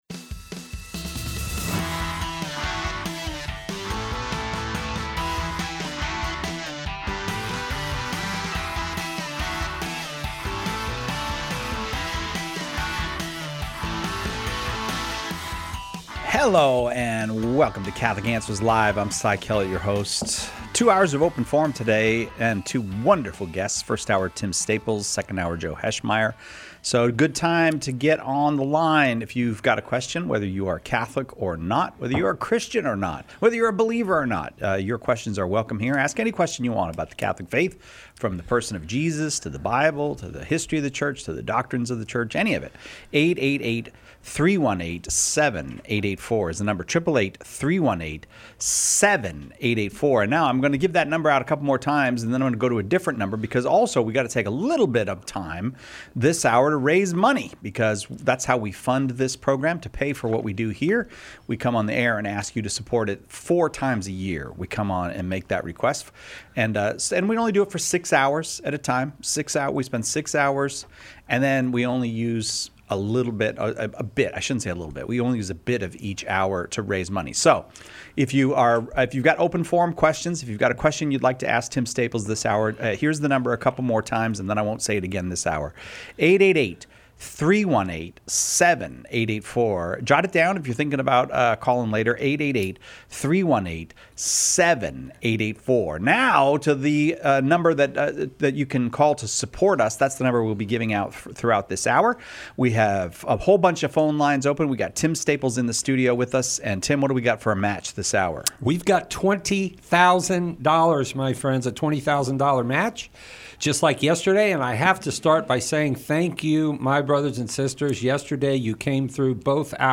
Open Forum